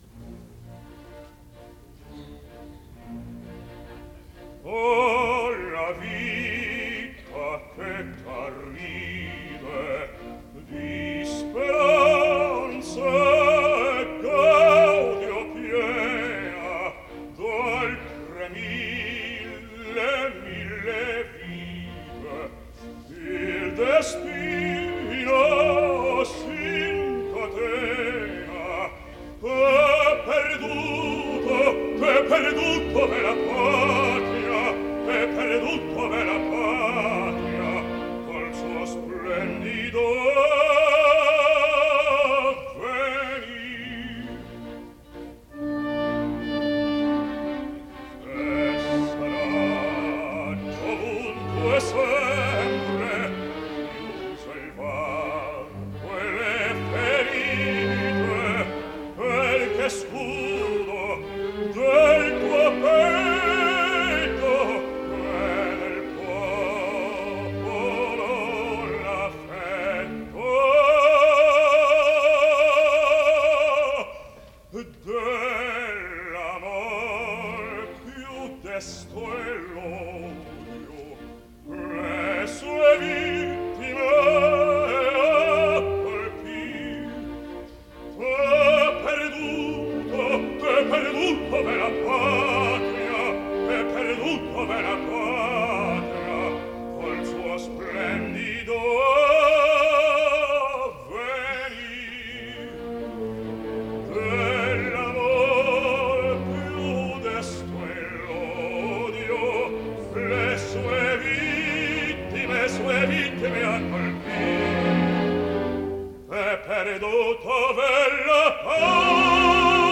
Запись 10 декабря 1955 года, Metropolitan Opera.